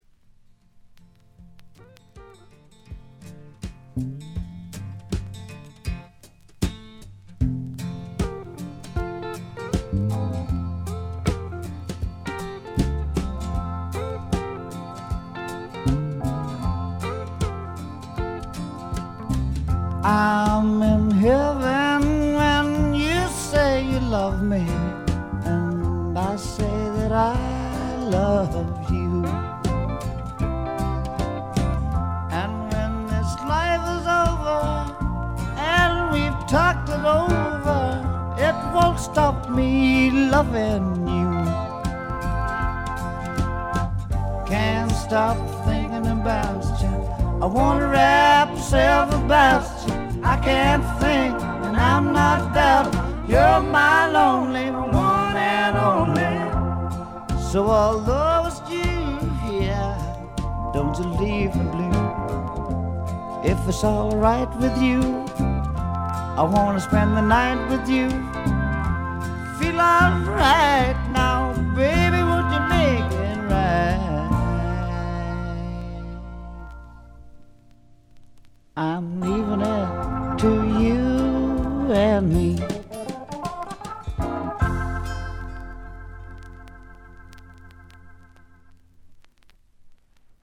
微細なノイズ感のみ。
試聴曲は現品からの取り込み音源です。